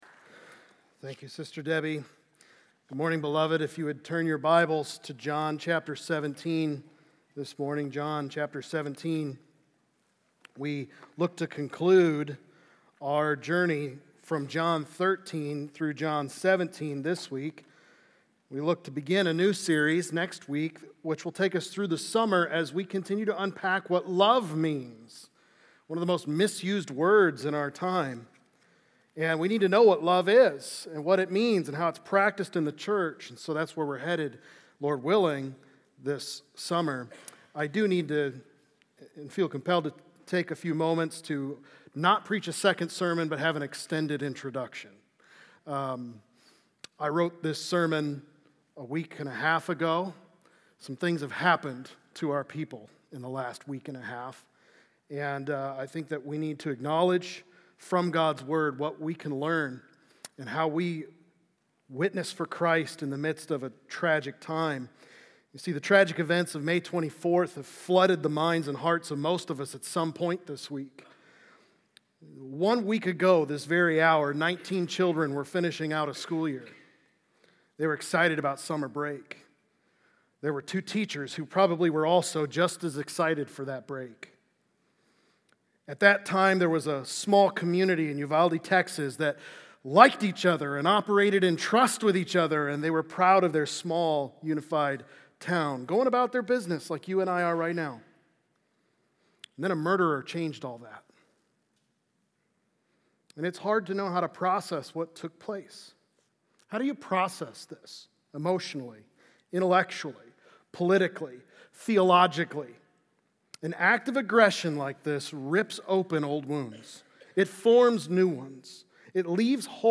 Until I Return, Grow In God’s Grace | Baptist Church in Jamestown, Ohio, dedicated to a spirit of unity, prayer, and spiritual growth